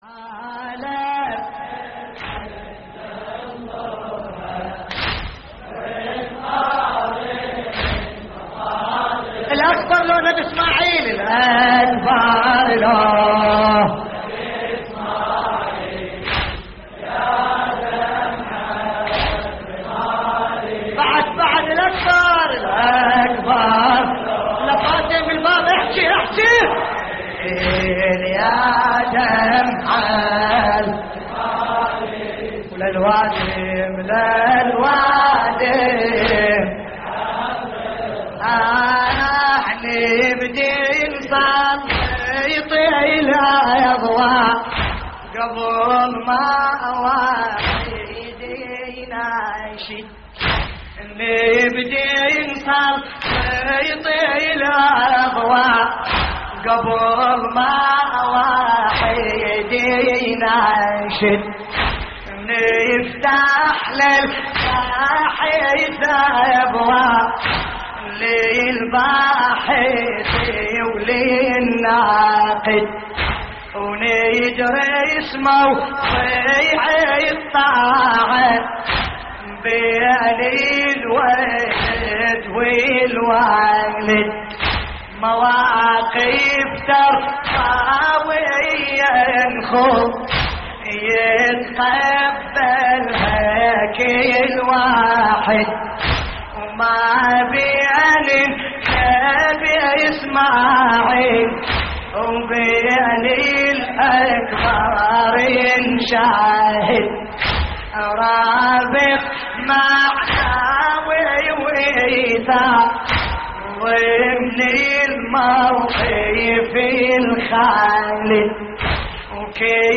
تحميل : حالة بحزن ننظرها ونقارن مصادرها الأكبر لو نبي / الرادود باسم الكربلائي / اللطميات الحسينية / موقع يا حسين